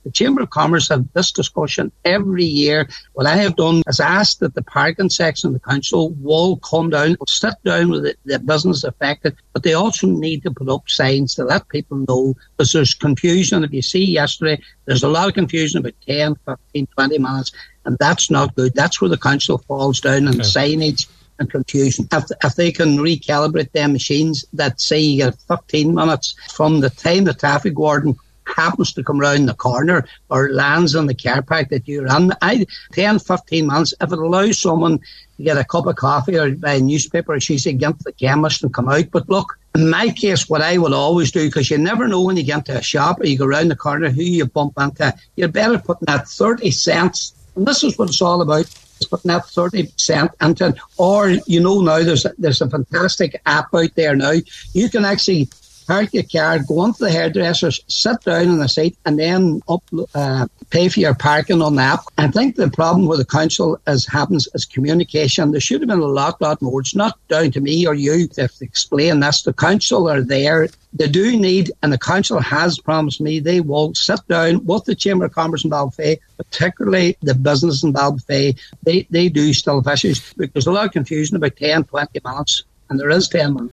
Patrick McGowan is Cathaoirleach of the Lifford Stranorlar Municipal District – He says one of the biggest issues is a lack of signage and communication, and that’s something he wants the council to address…………..